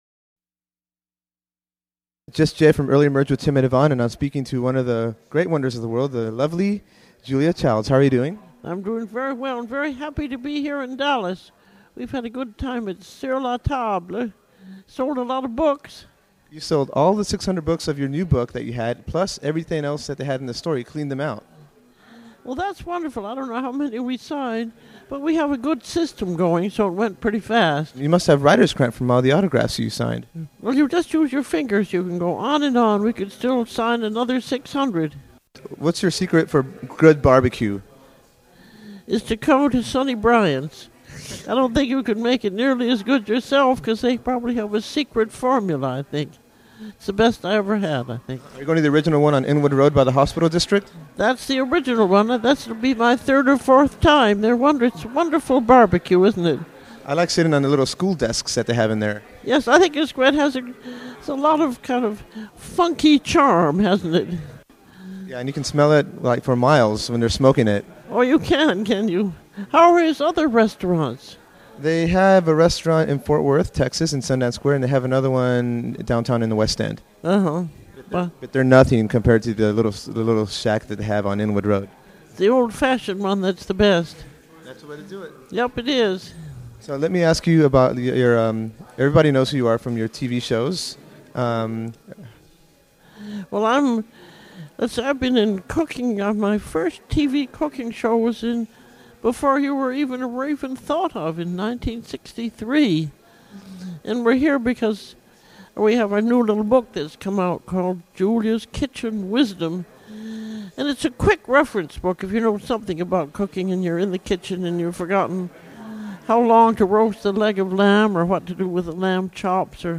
One day, Julia Child was doing a cooking demonstration and a book signing at Sur Le Table in Dallas and I had arranged to interview her afterwards. Needless to say, Mrs. Child was delightful and charming and we had a wonderful chat.
Julia_Child_Interview.mp3